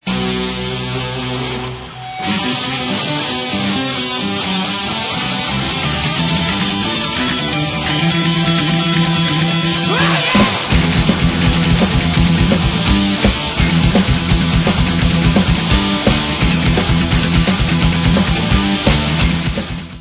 sledovat novinky v oddělení Heavy Metal
Rock